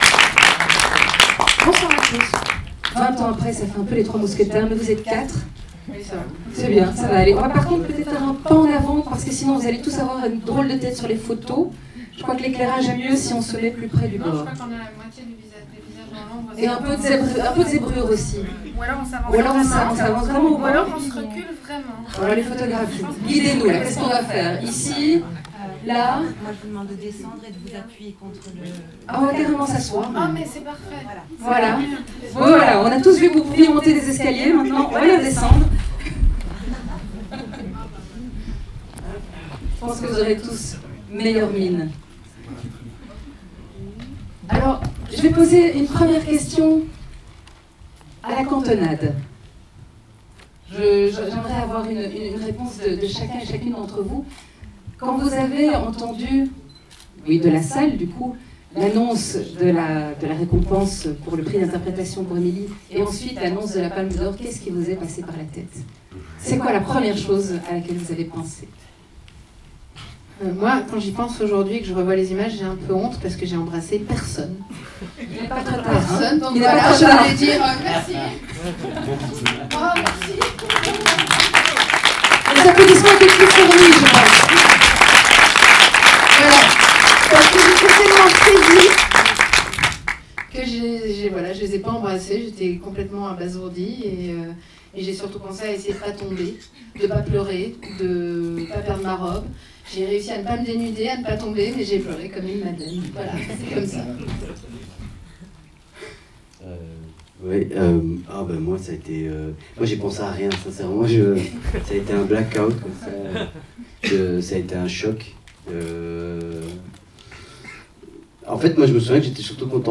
Om het jubileum van “Rosetta” te vieren, heeft het Brussels International Film Festival hen herenigd in de Brusselse Palace om een screening van “Rosetta” te introduceren, samen met acteur Fabrizio Rongione. Ze blikten terug op die glorieuze festivalavond in Cannes, haalden herinneringen op over hun samenwerking bij het maken van de film, en ze beklemtoonden het belang en de impact die “Rosetta” had op het verdere verloop van hun carrière.
Hier zijn enkele foto’s van het evenement—de rode loper gevolgd door de Q&A in de zaal—met de Franse audio van hun geanimeerde introductie onderaan deze post.